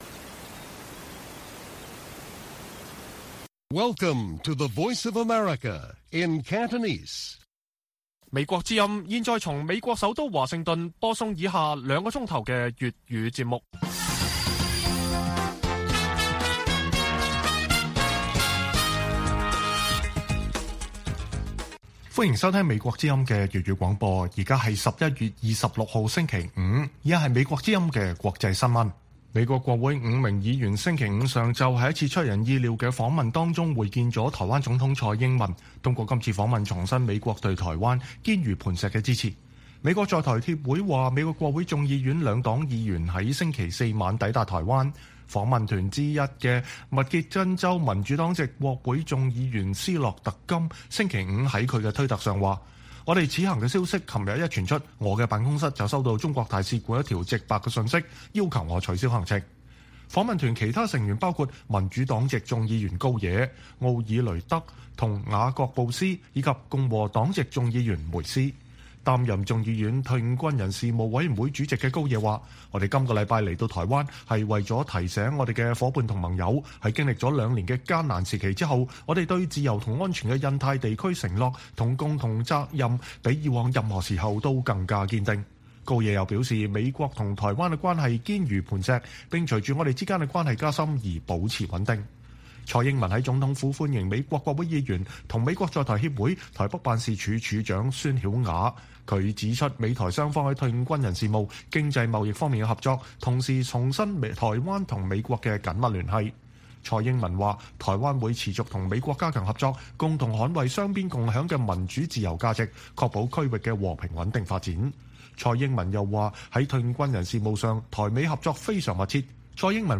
粵語新聞 晚上9-10點: 美國國會跨黨派議員訪台會見蔡英文